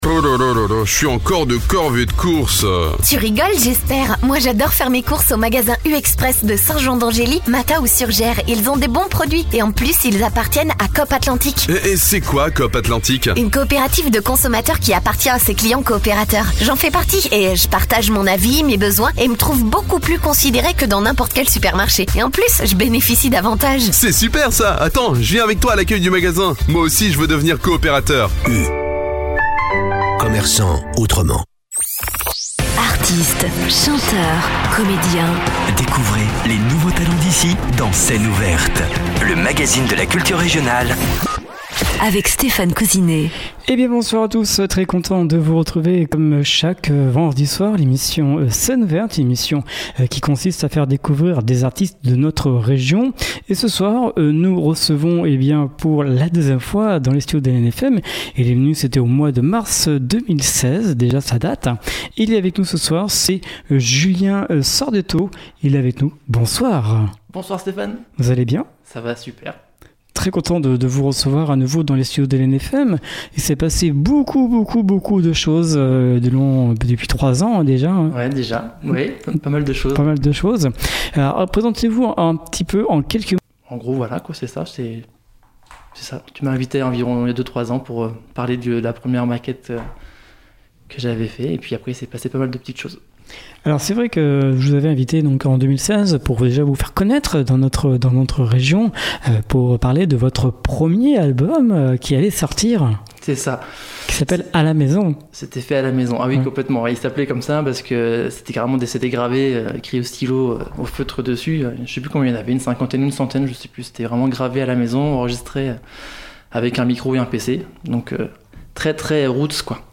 Guitare, cool et mélodies